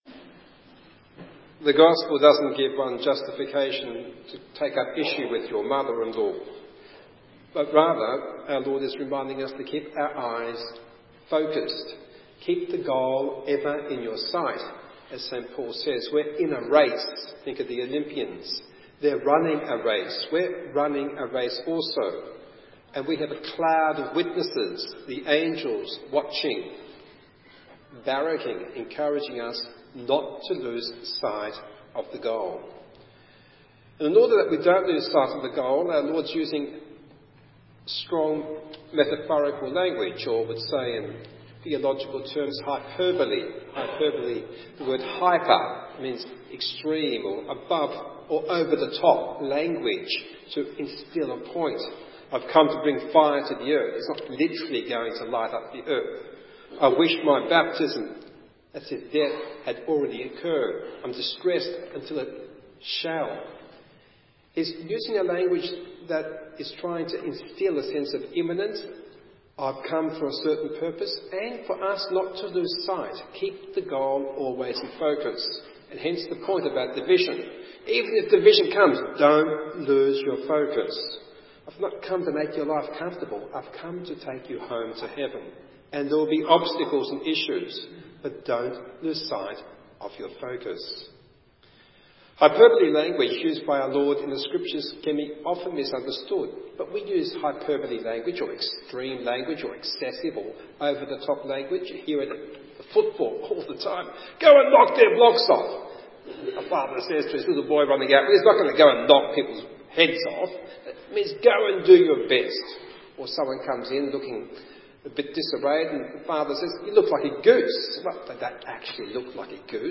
Recorded Homily, Sunday 14th August 2016: